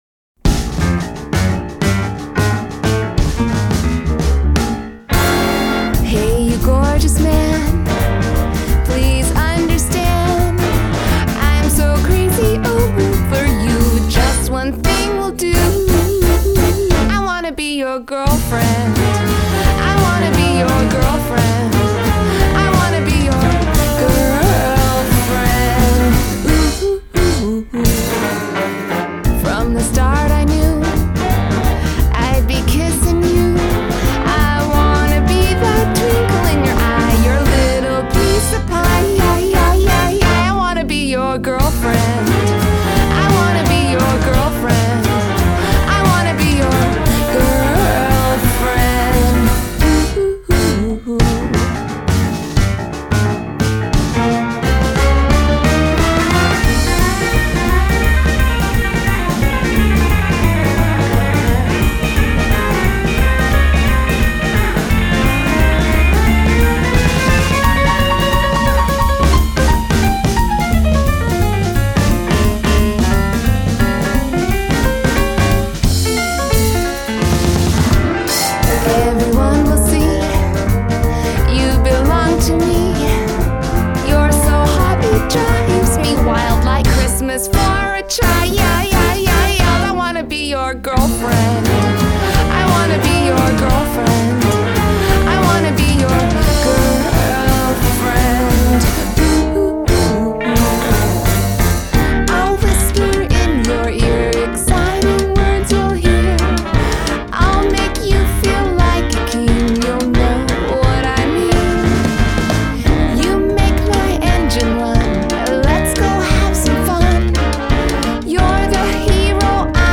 Adult Contemporary , Comedy , Indie Pop , Musical Theatre